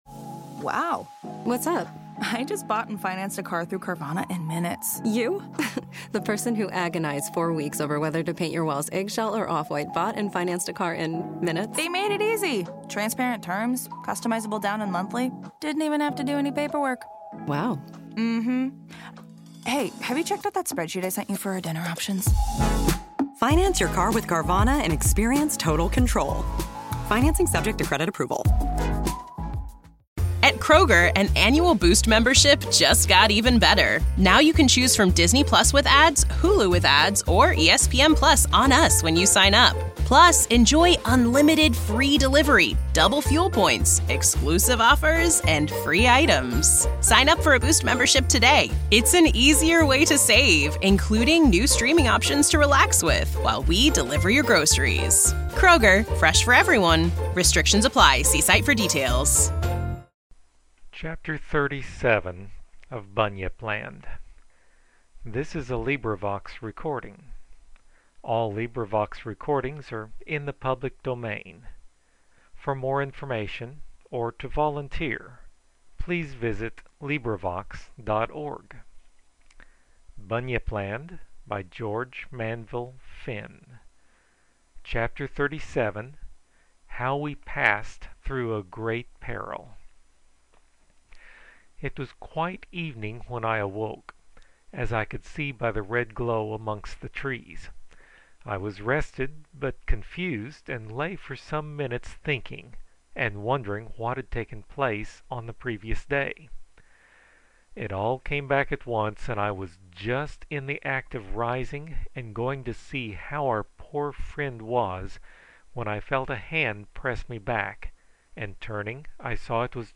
100 Great Audiobooks of Literary Masterpieces!